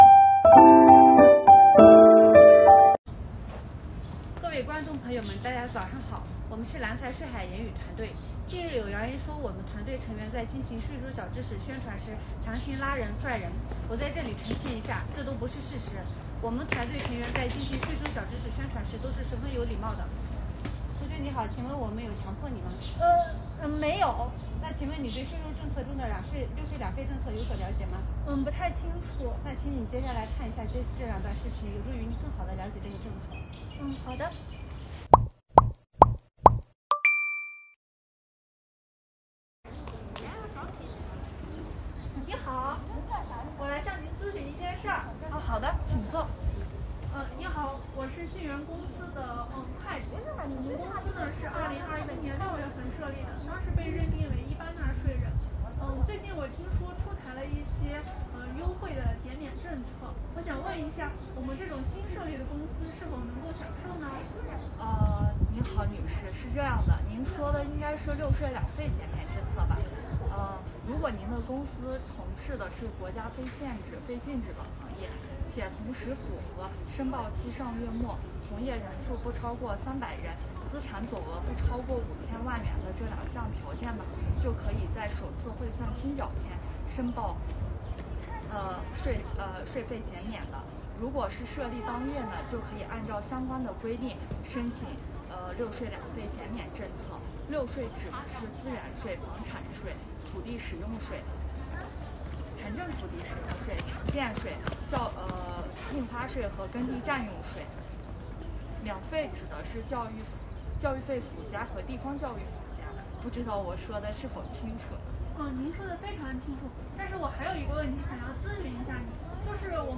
税收小剧场